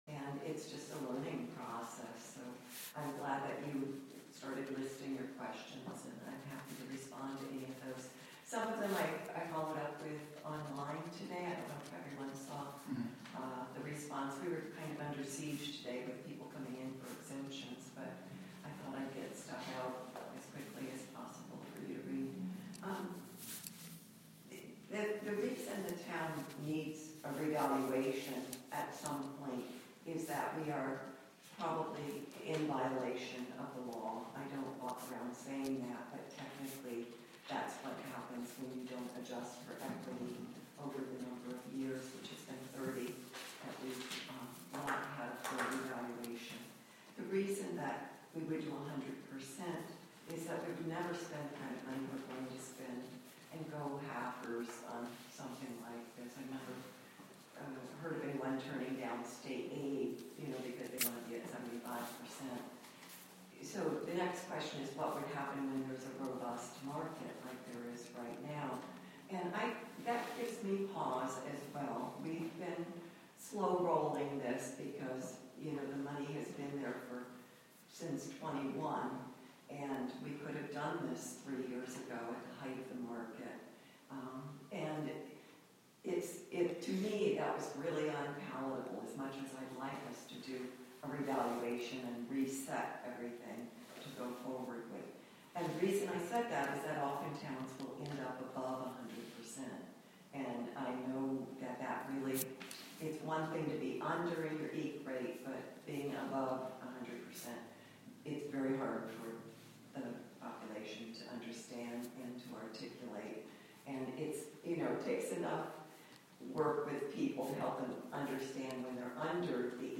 Live from the Town of Catskill: February 21, 2024 Town Board Meeting (Audio)